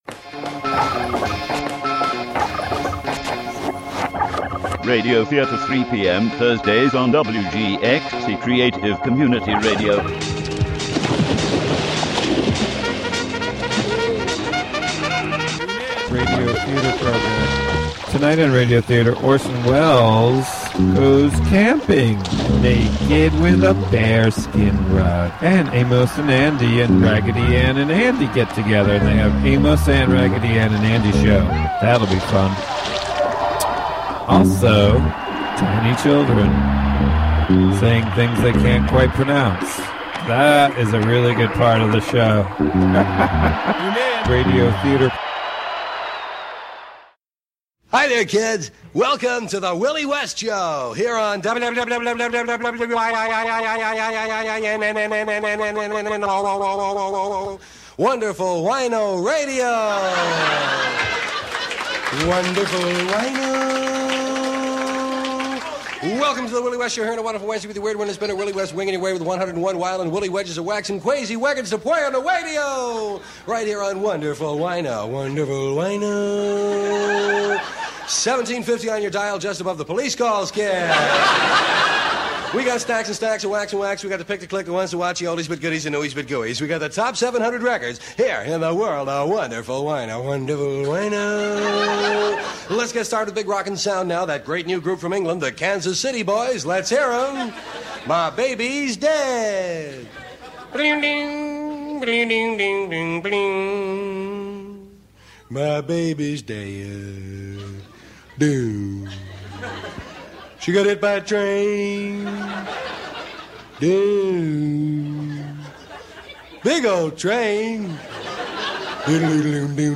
Radio Theatre: WINO Radio (Audio)